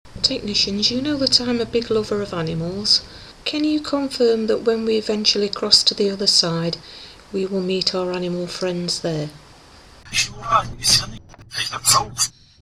This was recorded using just the Sony B300 and a reversed Russian background file.